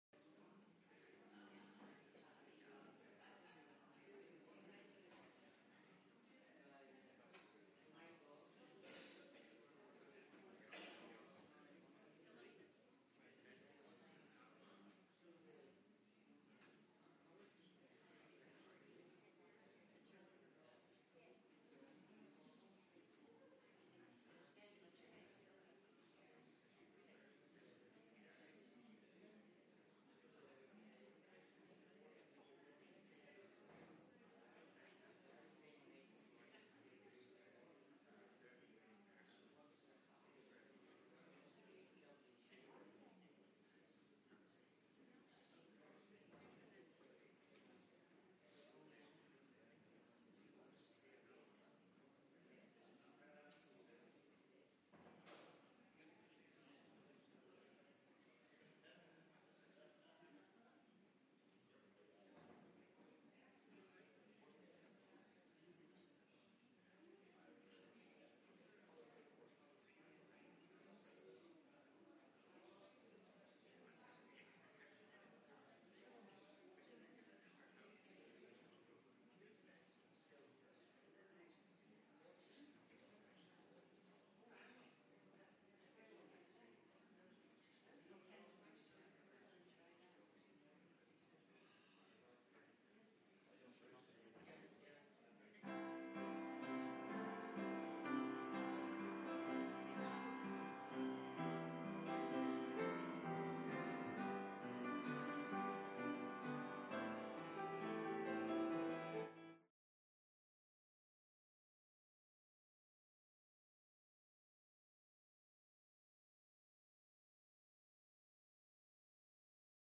Listen to the sermons - First Rathfriland Presbyterian Church